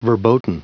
Prononciation du mot verboten en anglais (fichier audio)